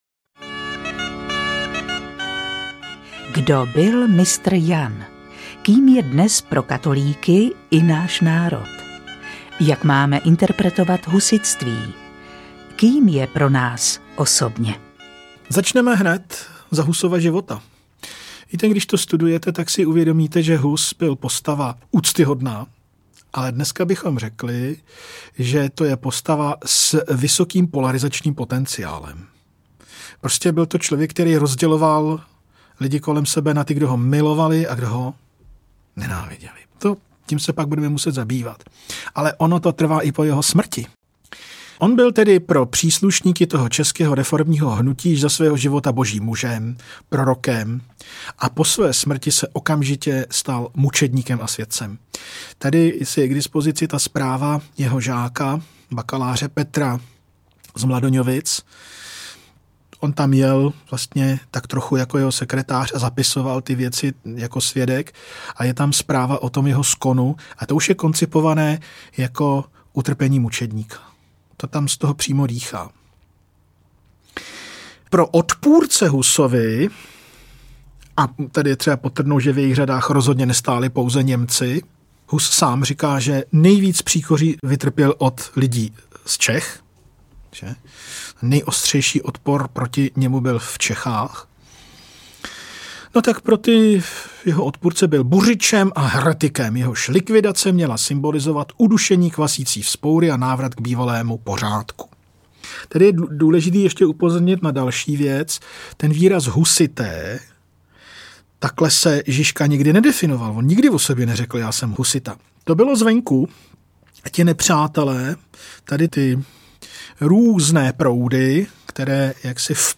Husovská dilemata audiokniha
Ukázka z knihy